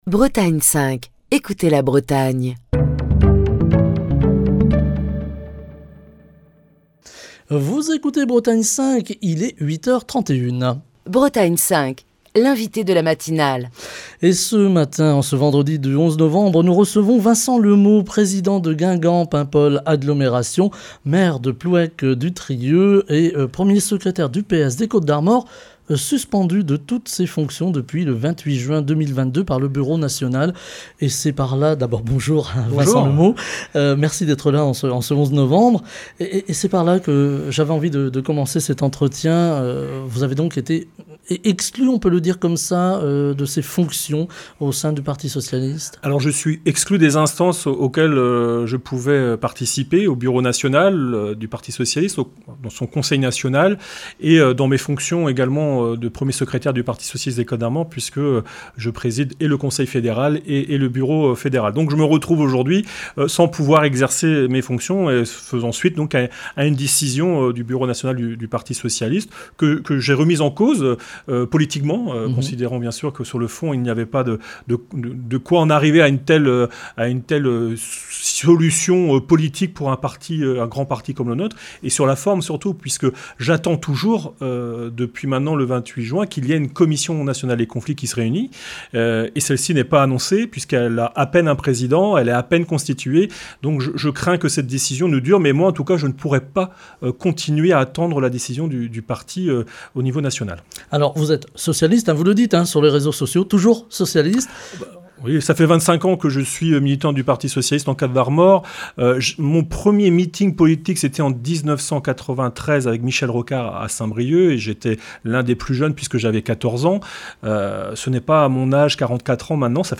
Le 28 juin dernier, le bureau national du PS décidait de suspendre les dissidents socialistes, qui avaient été candidats aux législatives contre la Nupes. C'est le cas de Vincent Le Meaux, président de Guingamp Paimpol Agglomération, maire de Plouëc-du-Trieux et encore premier secrétaire du Parti Socialiste des Côtes-d'Armor, qui est l'invité de la matinale de ce vendredi.